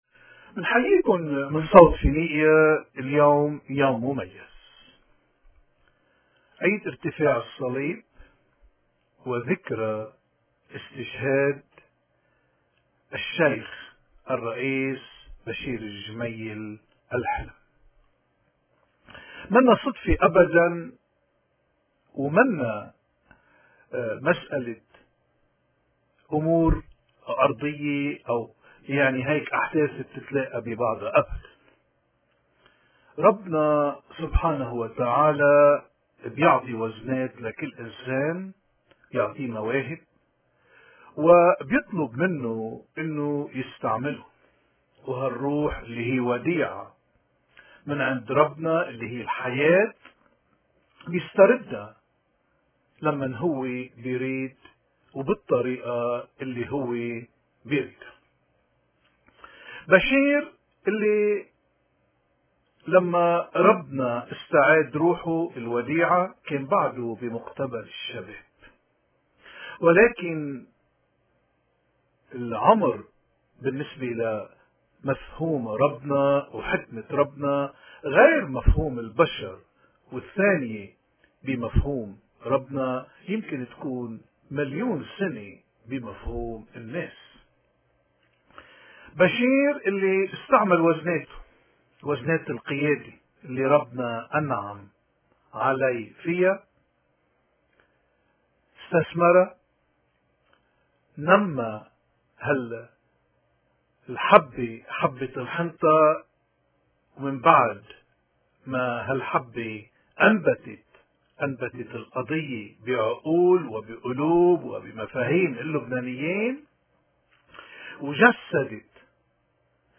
عظة